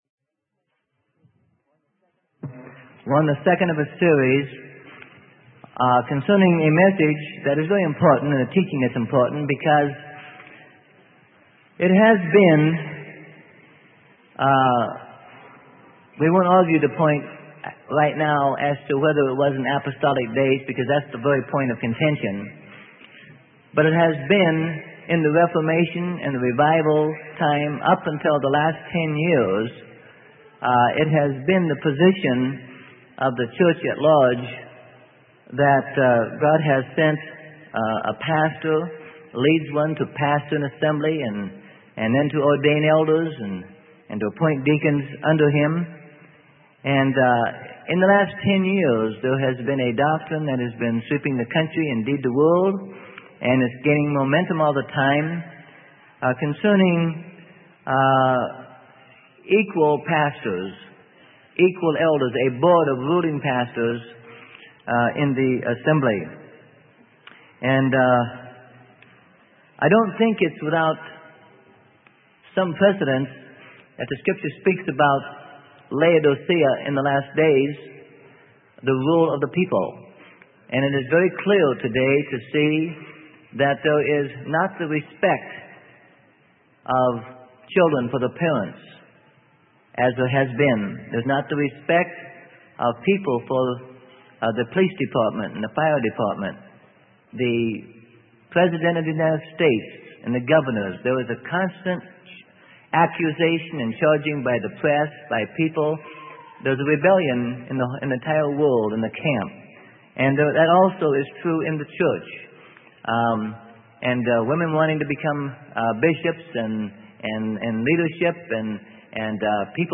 Sermon: Proper Church Authority-Rebuttal to Equal Eldership Concept - Part 2 - Freely Given Online Library